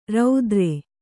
♪ raudre